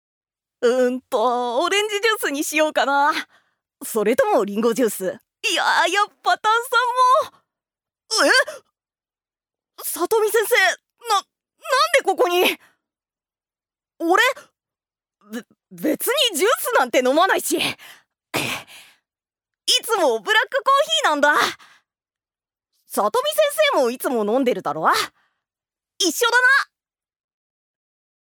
女性タレント
音声サンプル
セリフ６